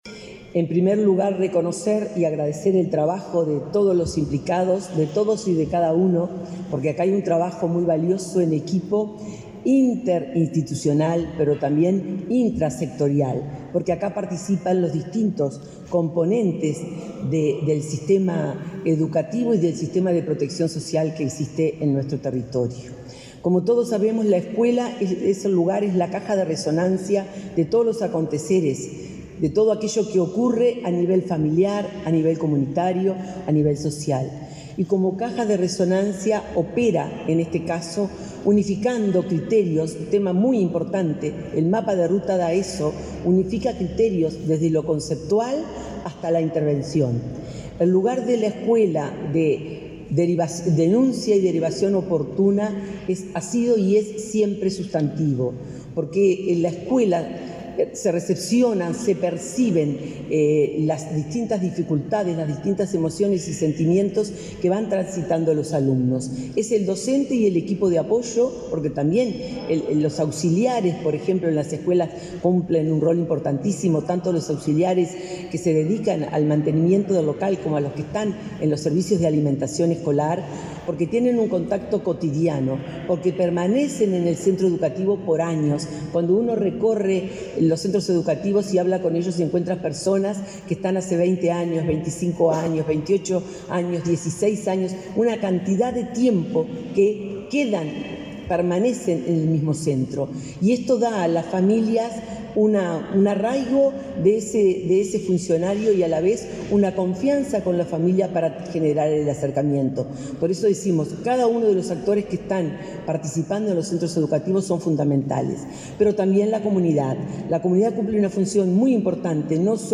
Palabras de autoridades de ANEP